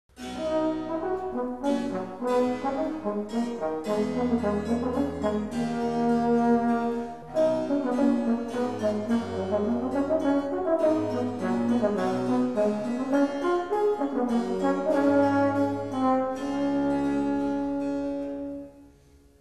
Sonata a 2 violini, trombone, violone - 08 Allegro[64K WMA